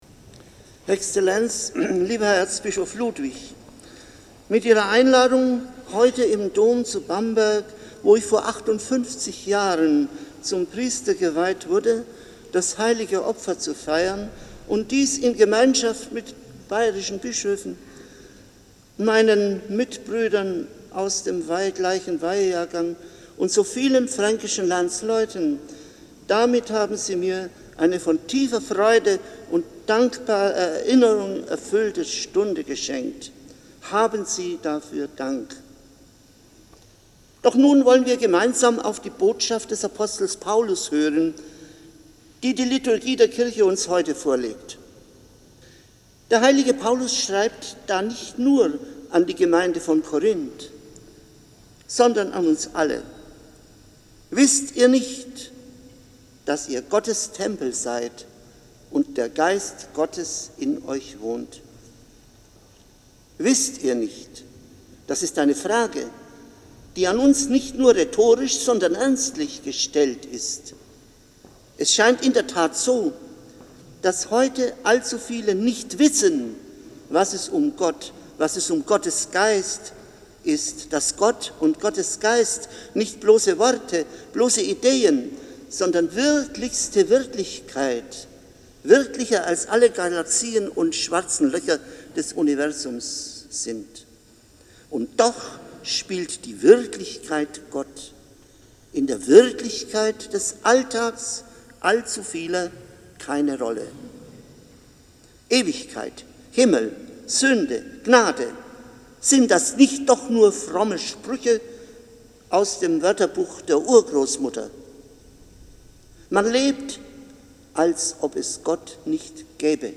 Predigt als mp3